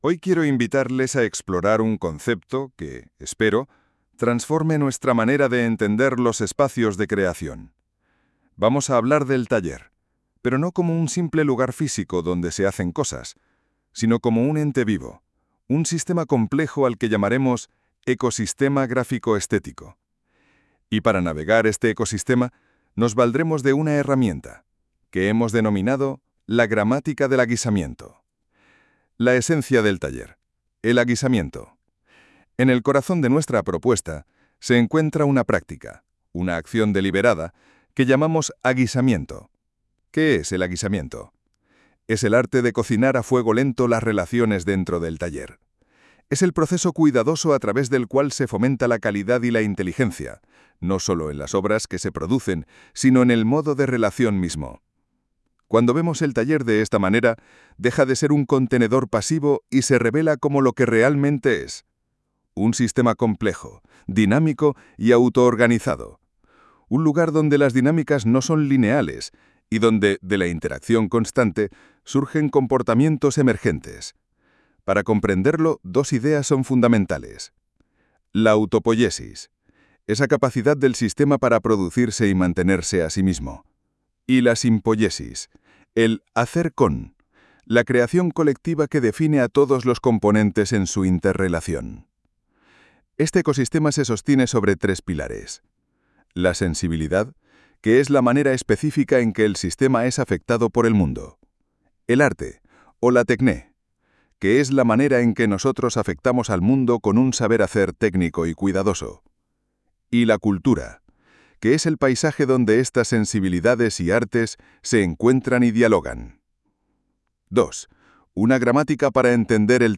por Audio generado con IA